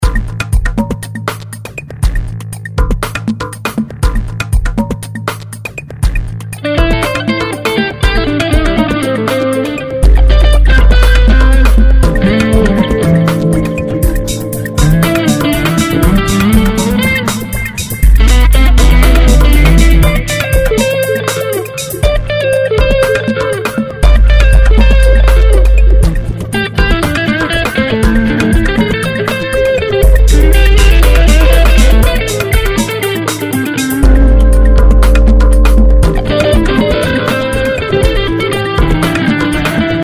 Jingles to listen to:
synthétiseur, chant, guitare, percussion, drums